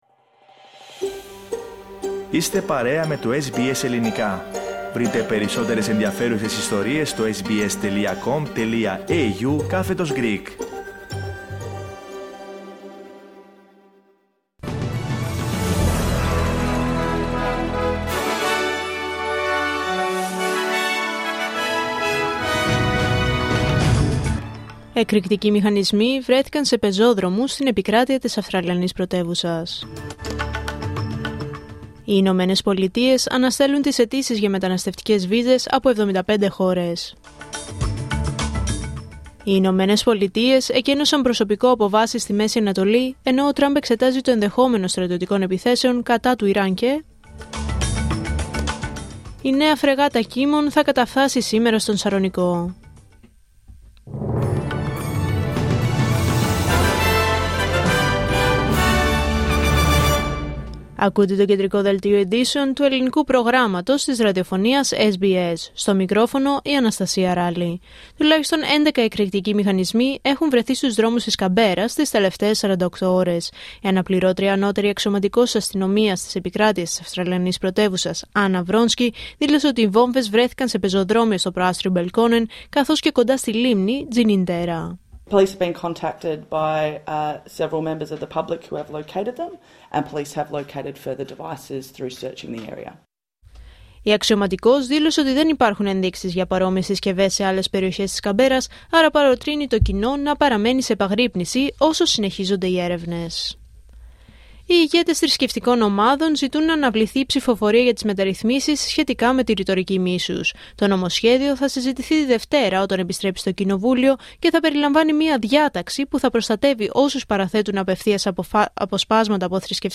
Δελτίο Ειδήσεων Πέμπτη 15 Ιανουαρίου 2026